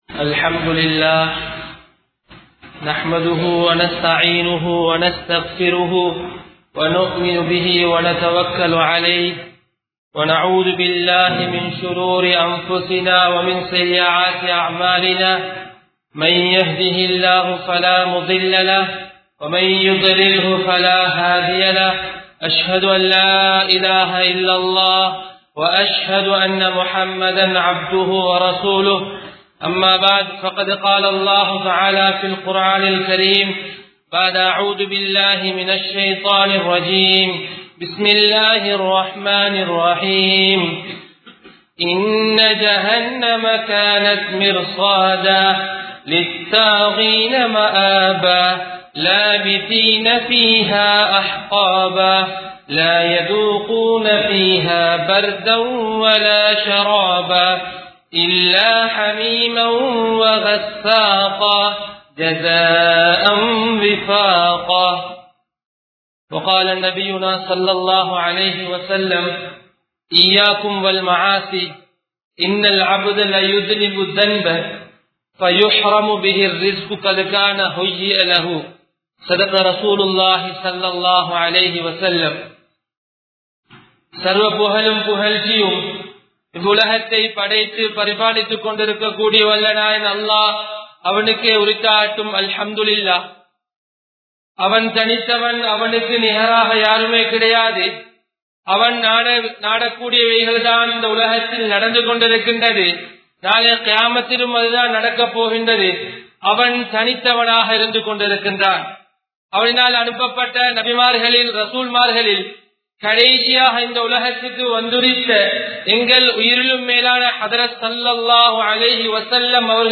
Paavathin Vilavukal! (பாவத்தின் விளைவுகள்!) | Audio Bayans | All Ceylon Muslim Youth Community | Addalaichenai